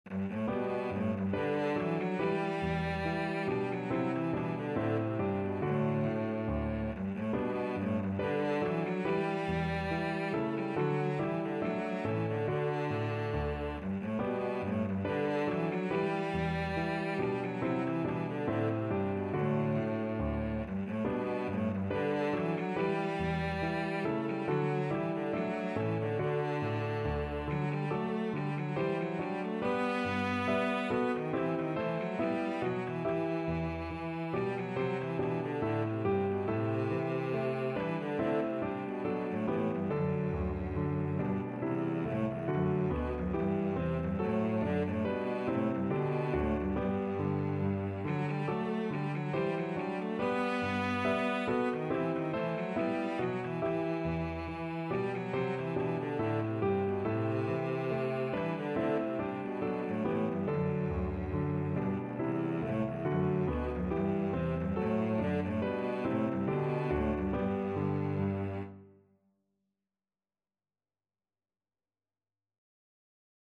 Free Sheet music for Cello
Cello
G major (Sounding Pitch) (View more G major Music for Cello )
4/4 (View more 4/4 Music)
Classical (View more Classical Cello Music)